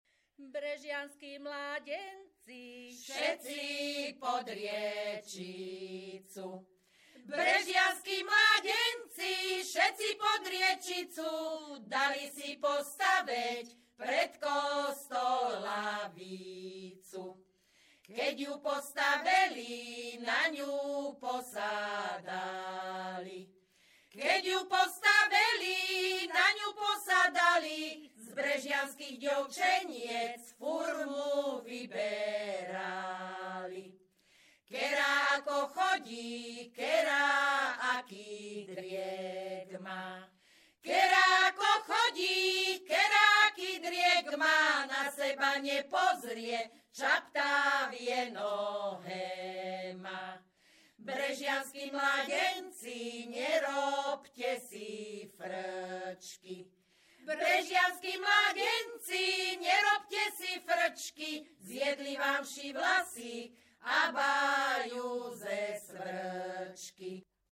Popis ženský skupinový spev bez hudobného sprievodu
Miesto záznamu Brehy
Kľúčové slová ľudová pieseň